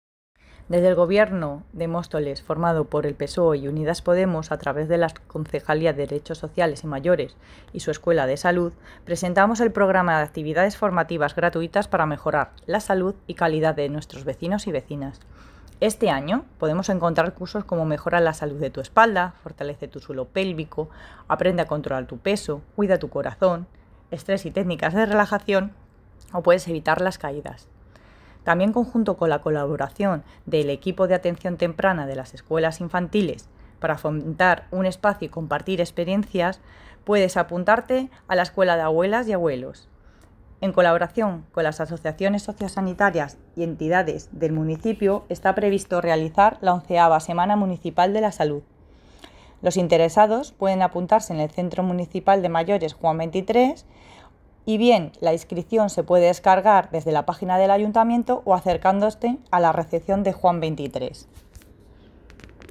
Declaraciones de Angela Viedma Concejala de Derechos Sociales y Mayores